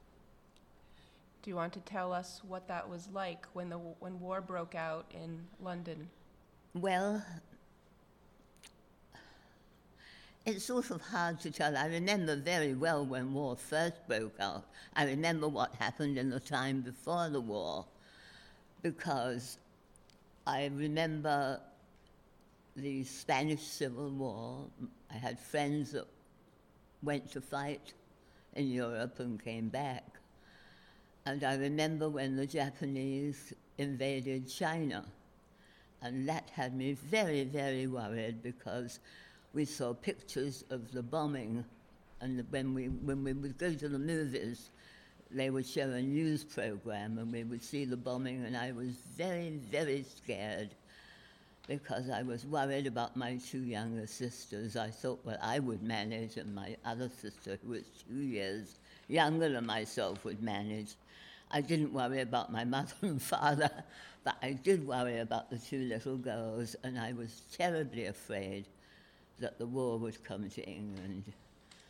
Date Created 2017-01-18 Type Conversation Duration 1 minute, 10 seconds Language English